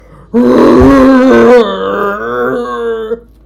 infinitefusion-e18 / Audio / SE / Cries / CHESNAUGHT.mp3